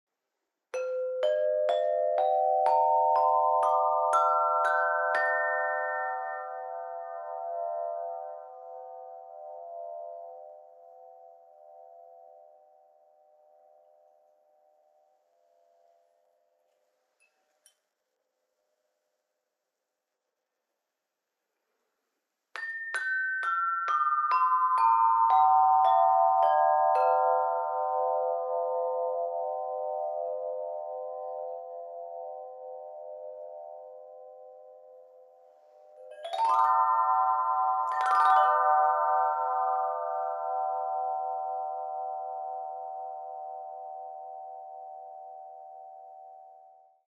Meinl Sonic Energy Meditation Flow Chime 38"/95 cm - 432 Hz/10 Töne/C Moll - Schwarz (MFC10CMIBK)
Die Röhren sind aus korrosionsbeständigem Aluminium gefertigt und können einzeln oder zusammen gespielt werden.